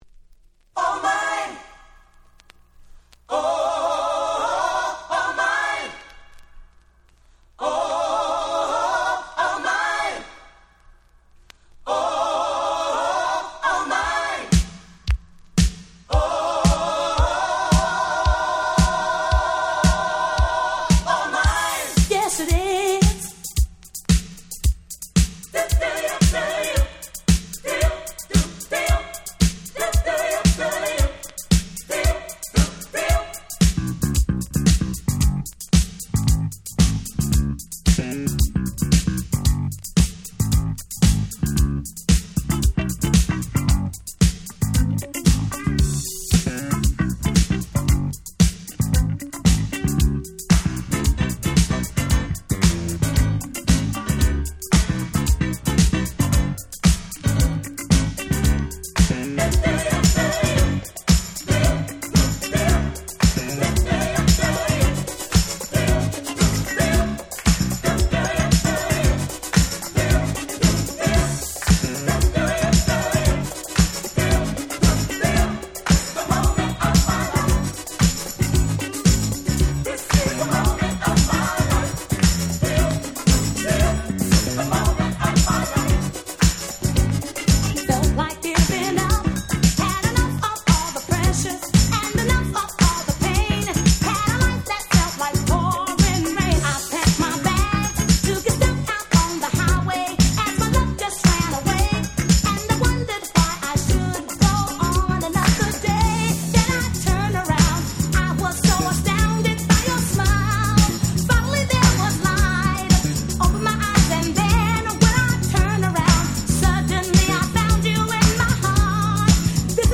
3曲共に言わずと知れた最高のDisco / Boogie !!